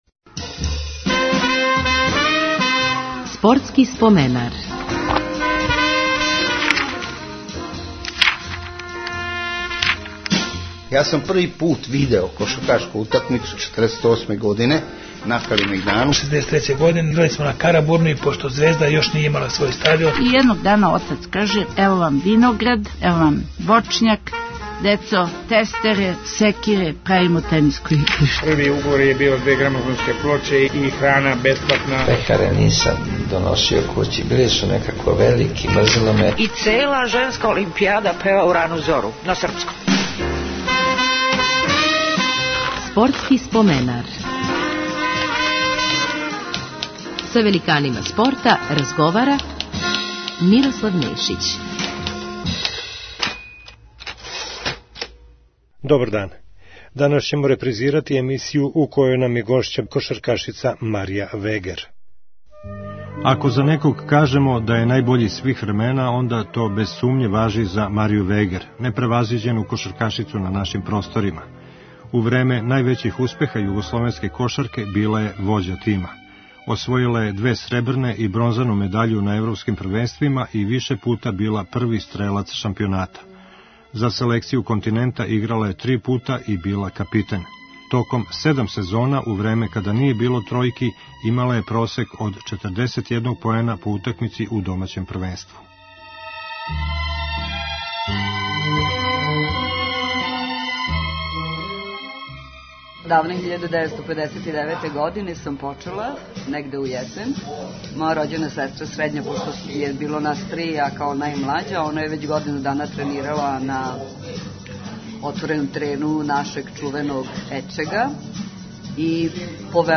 Репризирамо емисију у којој нам је гошћа наша најбоља кошаркашица свих времена Марија Вегер.